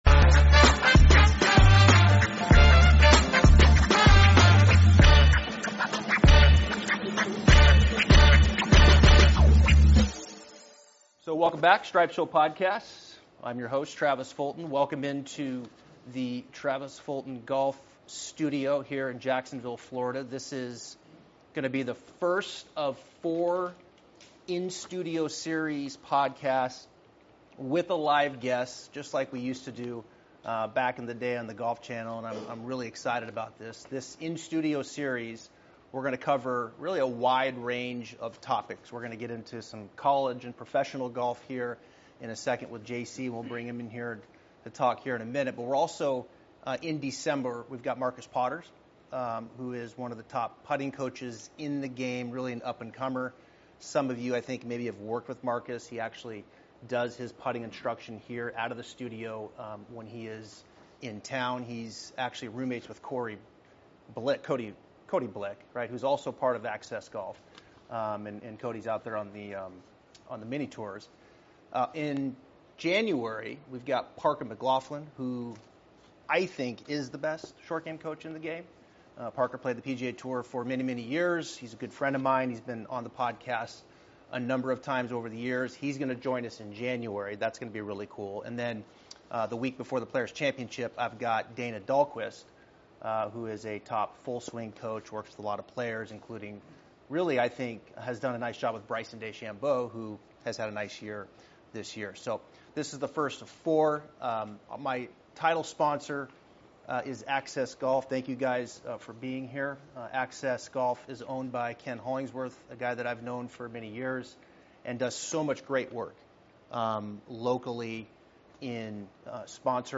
In-Studio Series